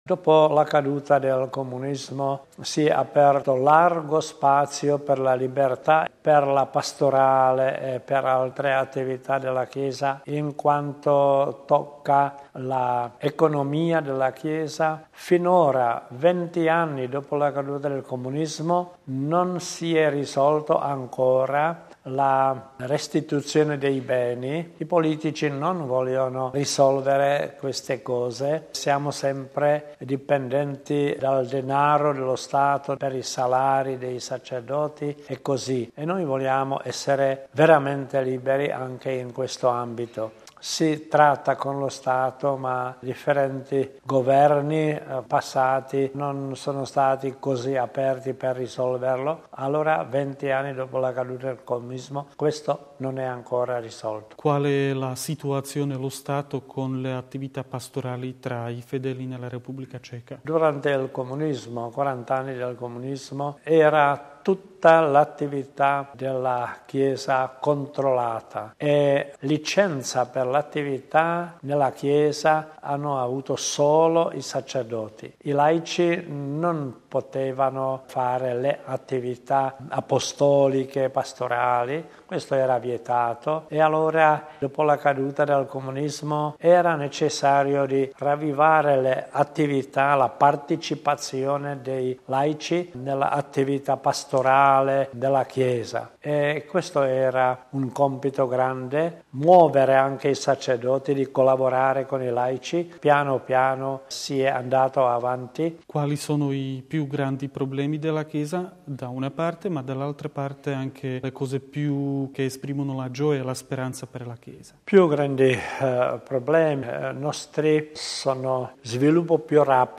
Cosa è cambiato in questi anni per la Chiesa? Ascoltiamo il cardinale arcivescovo di Praga, Miloslav Vlk, in questa intervista rilasciata alla Tv cattolica slovacca Tele Lux: